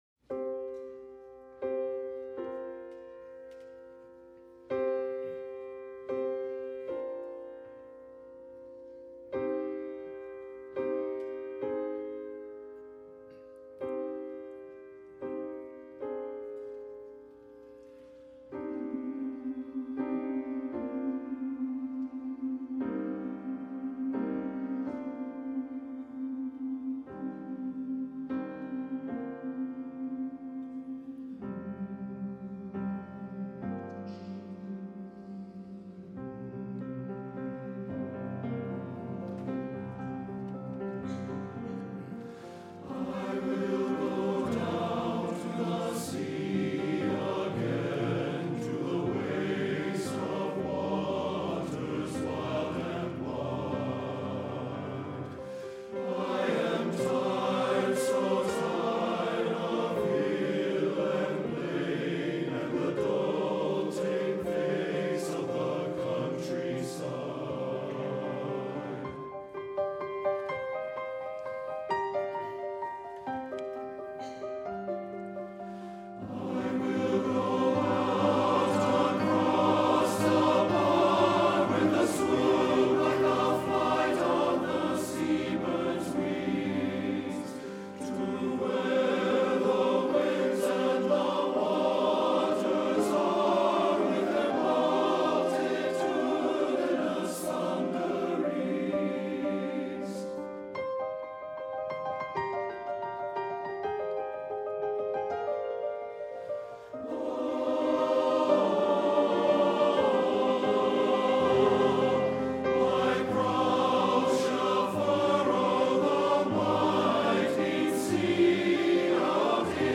This is sustained and powerful music, with the rolling waves often portrayed in the piano accompaniment.
downtothesea-ttbb.mp3